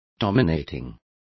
Complete with pronunciation of the translation of dominating.